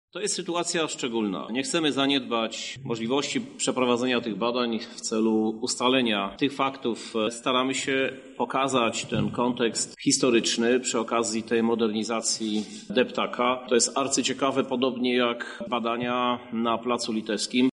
-Te znaleziska są dla nas wyjątkowym szczęściem – mówi prezydent Lublina, Krzysztof Żuk.